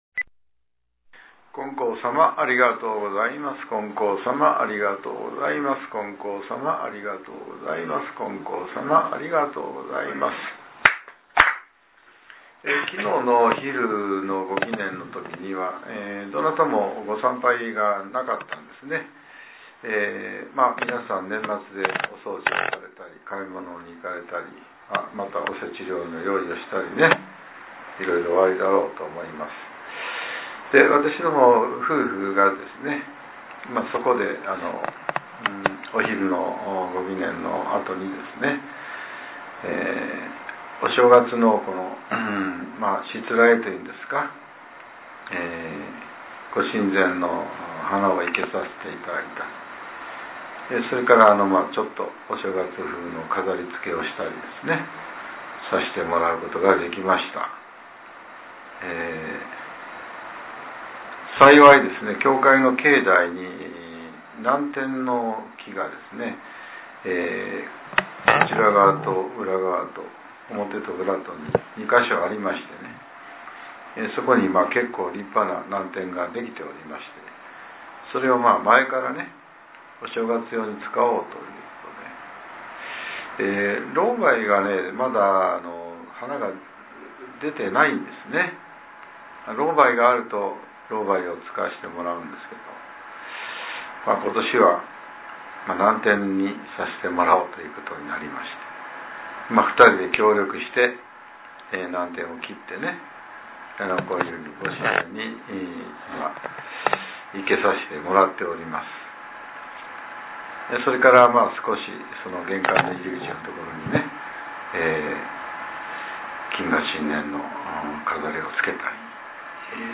令和６年１２月２９日（朝）のお話が、音声ブログとして更新されています。